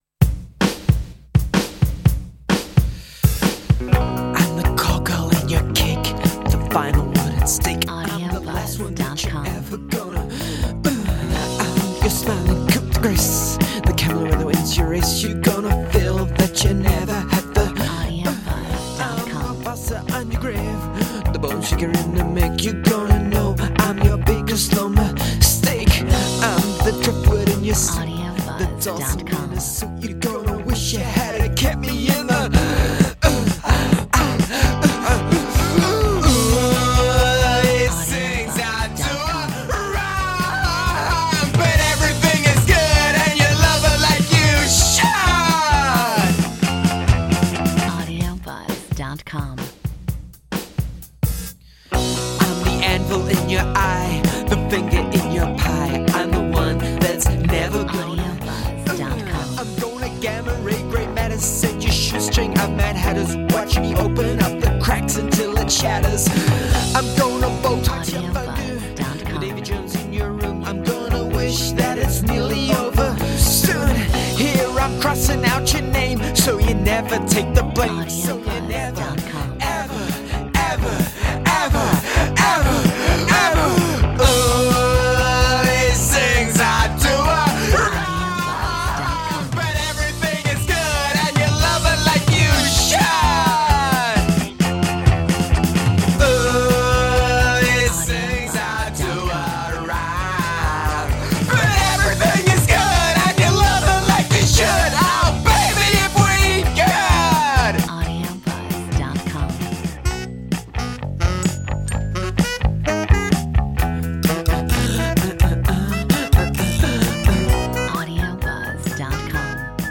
Metronome 130